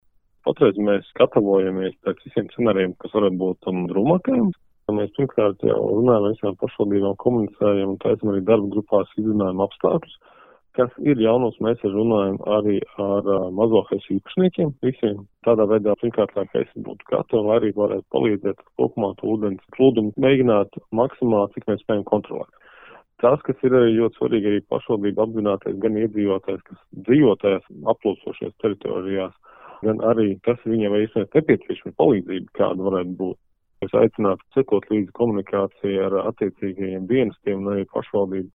Saruna ar Klimata un enerģētikas ministru Kasparu Melni
KEM_ministrs_Kaspars_Melnis_par_pludu_risku.mp3